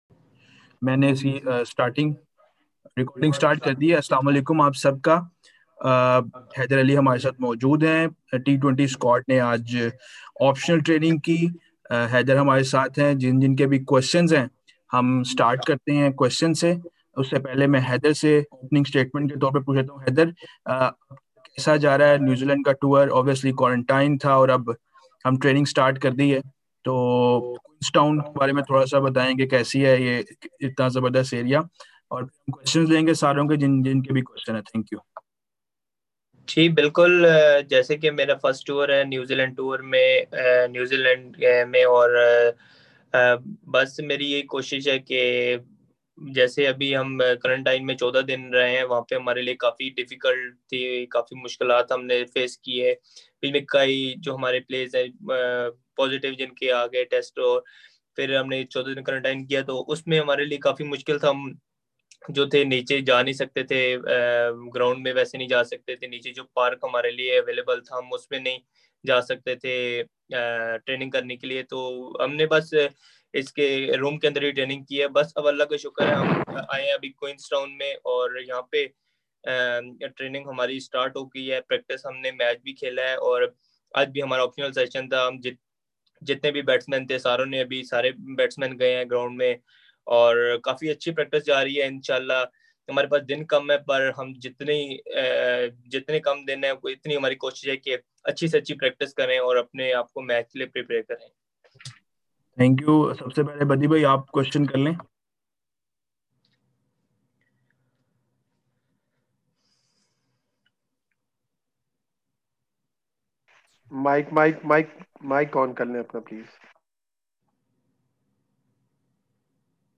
Pakistan batsman Haider Ali held a virtual media conference with the Pakistan media today